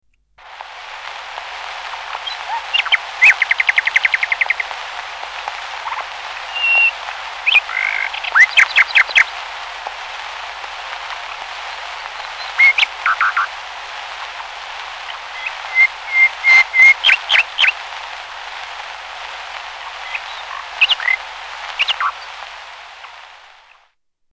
Nightingale
Category: Animals/Nature   Right: Personal
Tags: Science and Nature Wildlife sounds Bristish Animals British Wildlife sounds United Kingdom